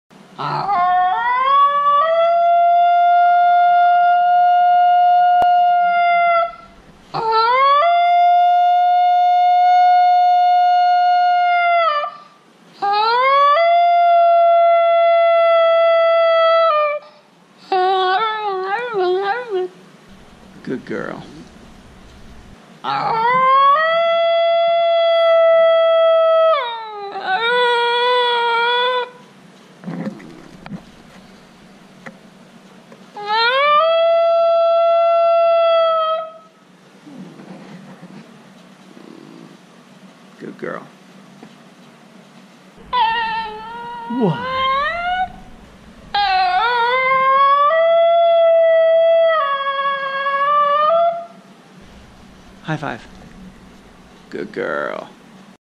Basenji Yodel Sound Button - Free Download & Play
Dog Barking Sound579 views